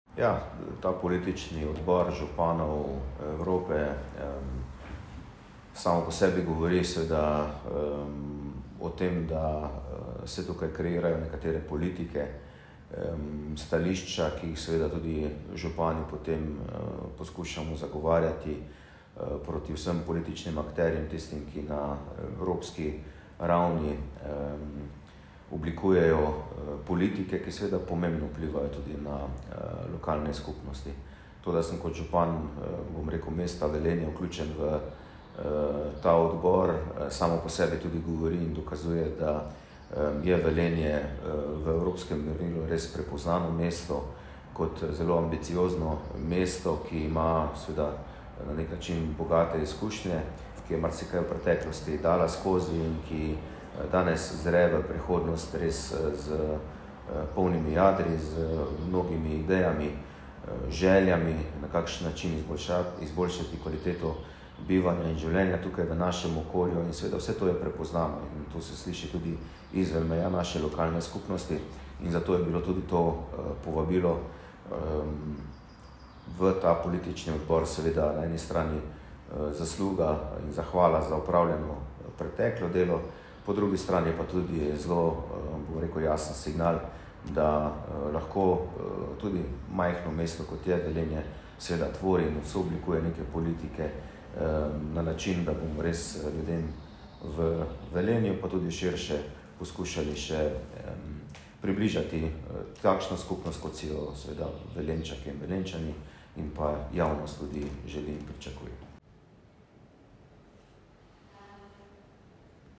Tonska izjava župana Mestne občine Velenje Petra Dermola je objavljena tukaj.
Tonska izjava župana Petra Dermola